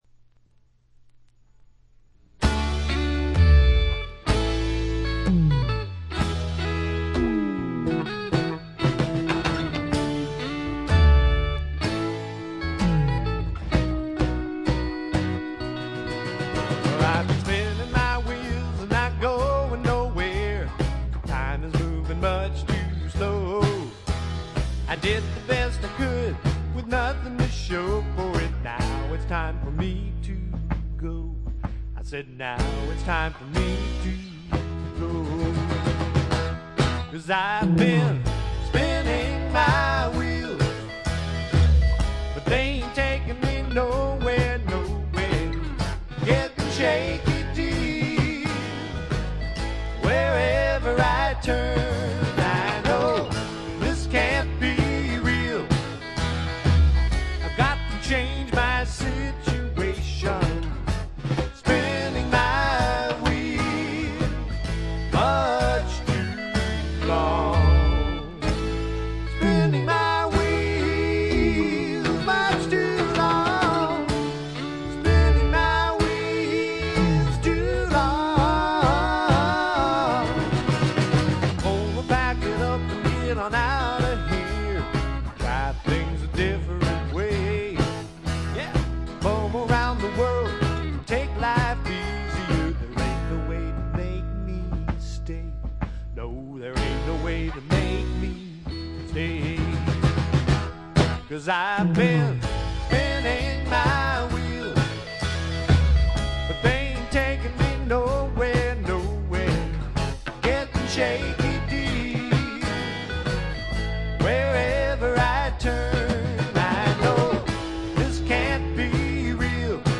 ほとんどノイズ感無し。
サンディエゴのシンガー・ソングライターによる自主制作盤。
試聴曲は現品からの取り込み音源です。